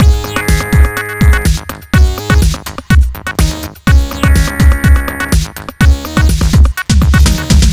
Bleep Hop Full.wav